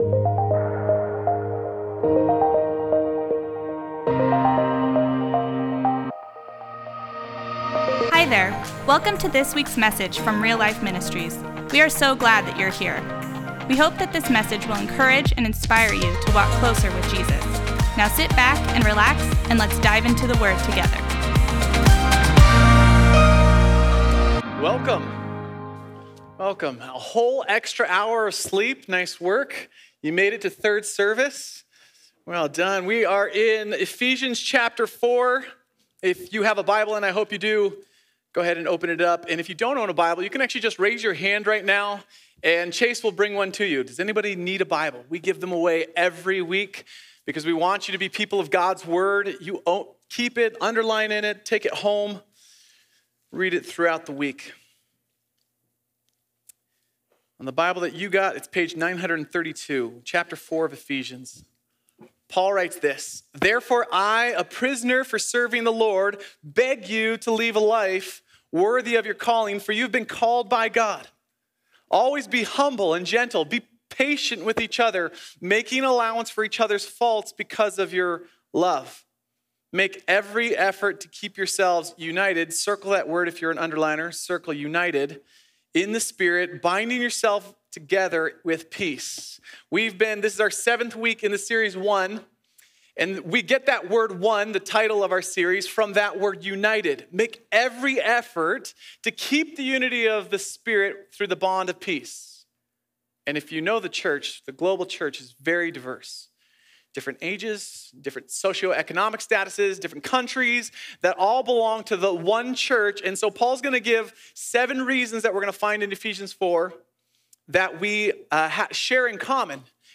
Other Sermon in this Series